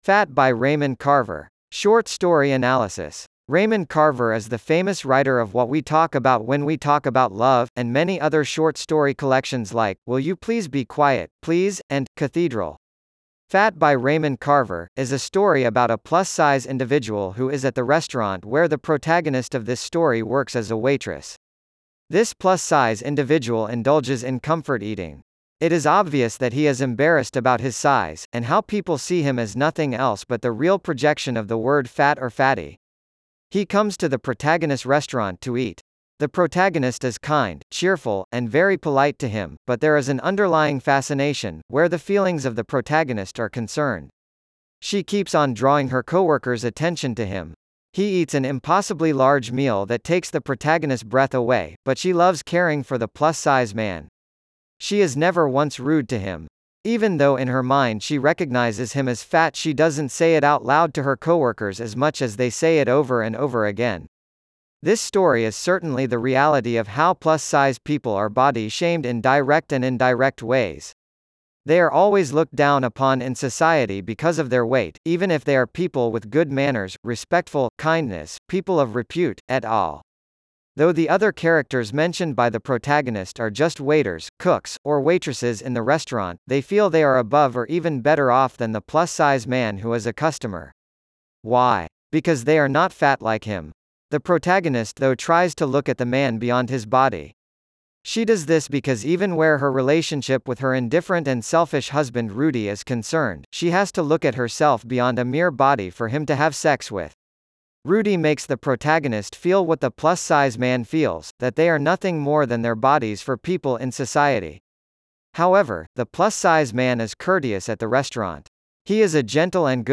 Fat-by-Raymond-Carver-Short-Story-Analysis_en-US-Wavenet-A.wav